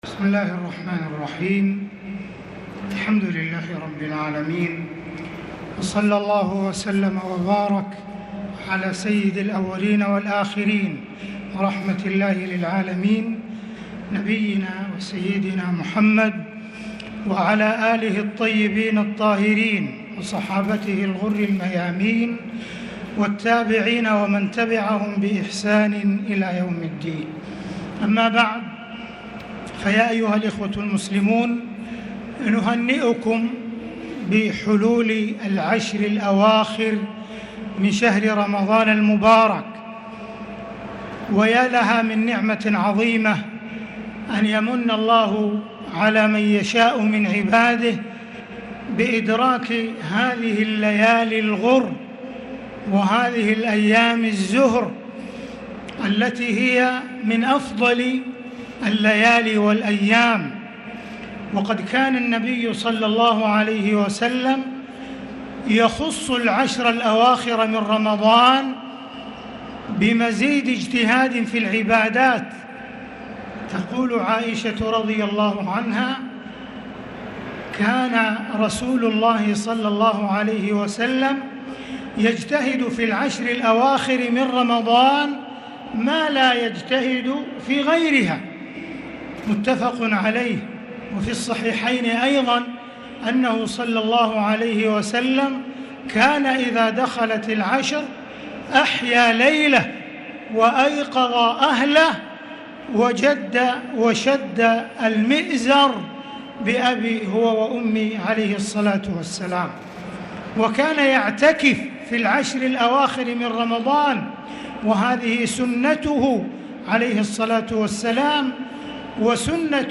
كلمة معالي الرئيس العام الشيخ أ.د.عبدالرحمن السديس بمناسبة حلول العشر الأواخر من شهر رمضان المبارك.